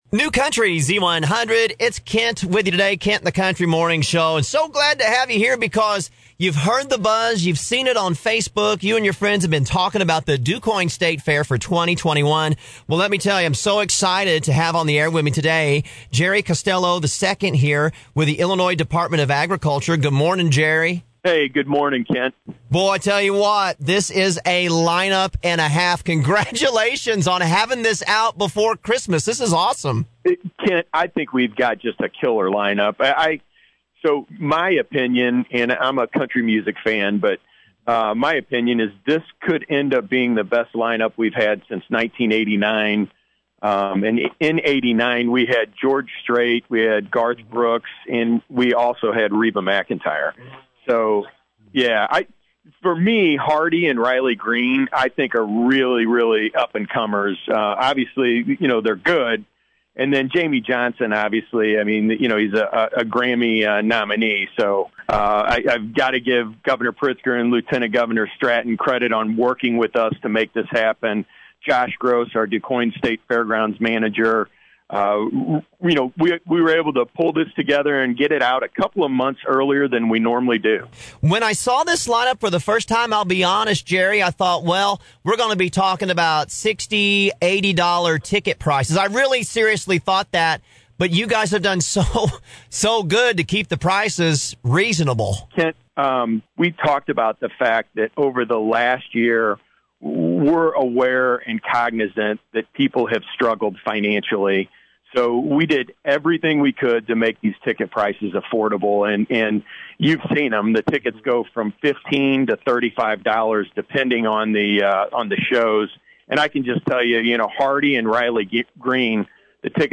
Jerry-Costello-II-on-DuQuoin-State-Fair-2021.mp3